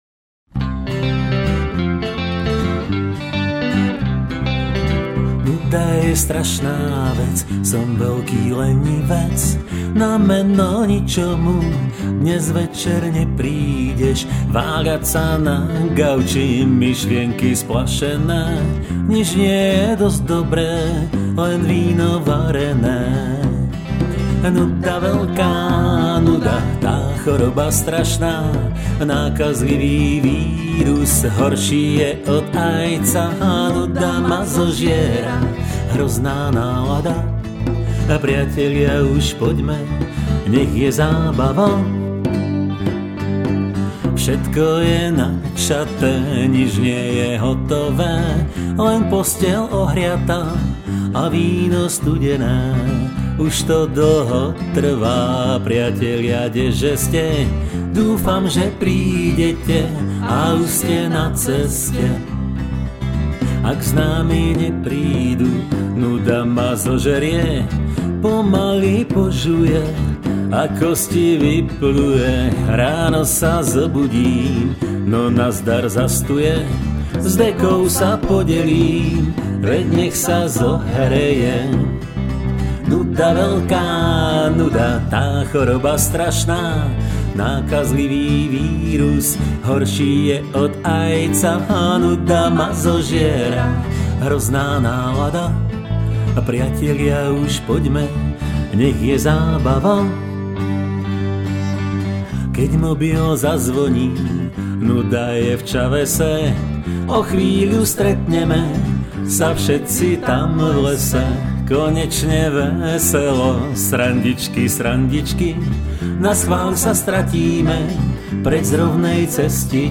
12 st. gitara, djembe, fúkacia harmonika, koncovka